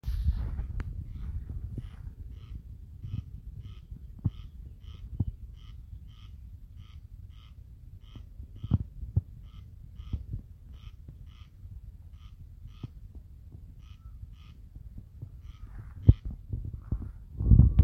Birds -> Rails ->
Corn Crake, Crex crex
StatusVoice, calls heard